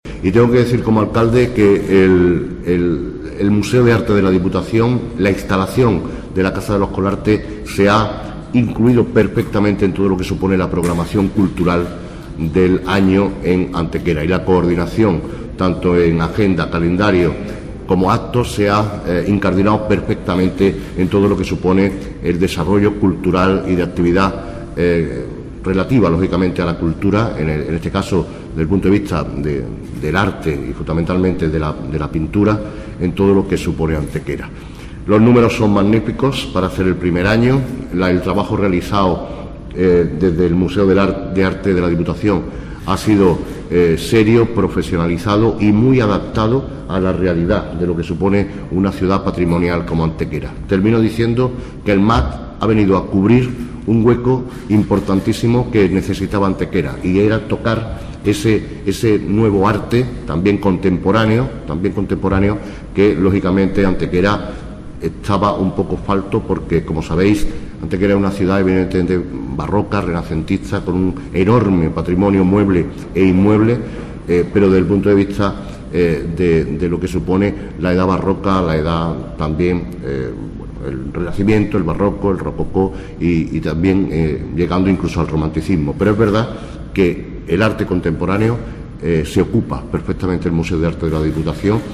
Esta rueda de prensa se enmarca dentro de la segunda jornada de la Semana de Antequera en Málaga (SAM 2016).
Cortes de voz